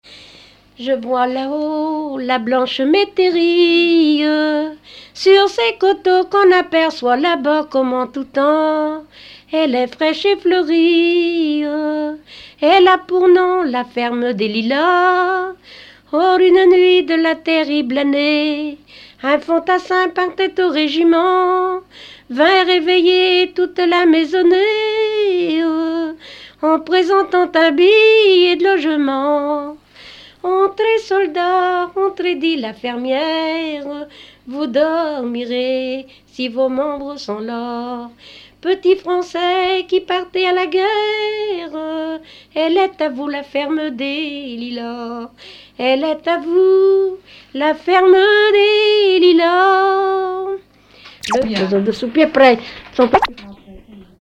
Genre strophique
Catégorie Pièce musicale inédite